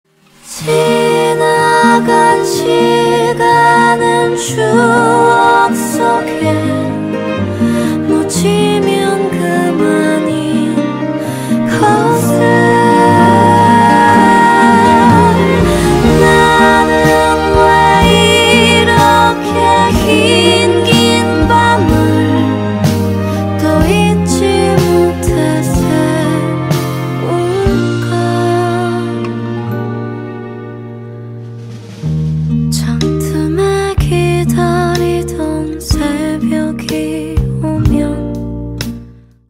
이건 하이라이트